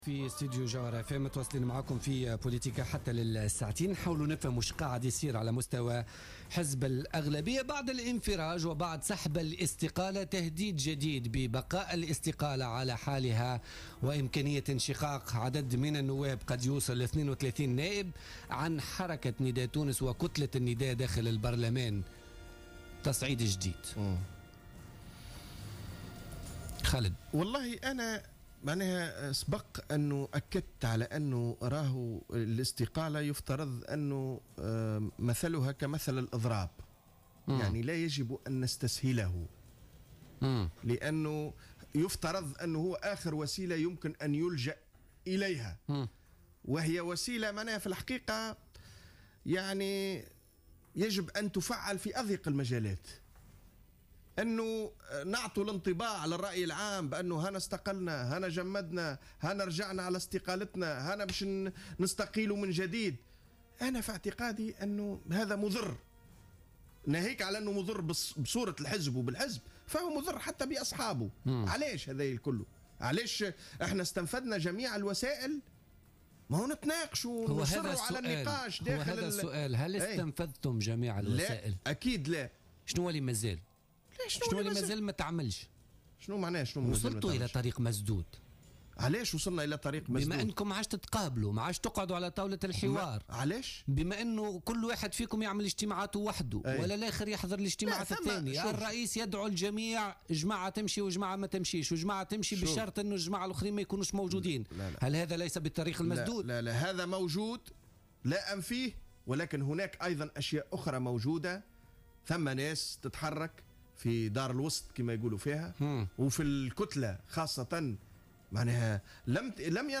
أكد القيادي بحركة نداء تونس خالد شوكات ضيف برنامج بوليتيكا اليوم الإثنين 23 نوفمبر 2015 أن قرار الإستقالة من الحزب يفترض أن يكون اخر وسيلة يمكن أن يلجأ اليها وهي وسيلة يجب أن تفعل في أضيق المجالات حتى لاتعطي انطباعا سيئا يضر بصورة الحزب وصورة أصحابه على حد قوله.